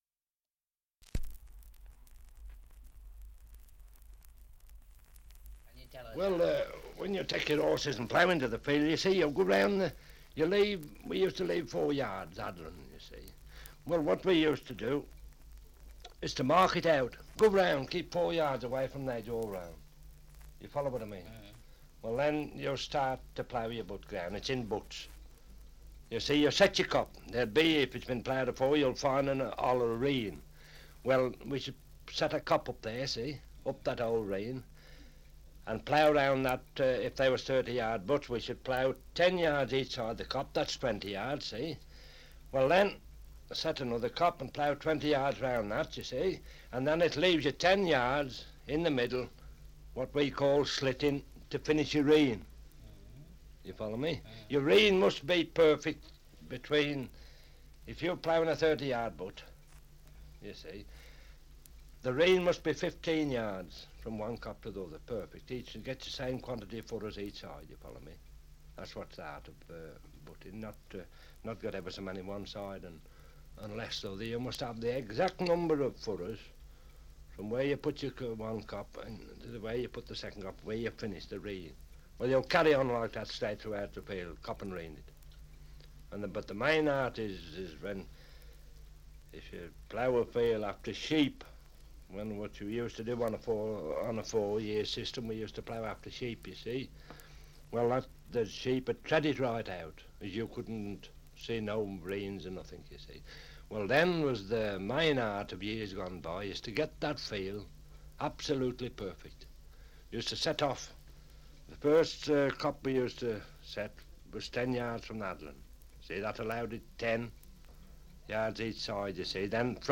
2 - Survey of English Dialects recording in Lapley, Staffordshire
78 r.p.m., cellulose nitrate on aluminium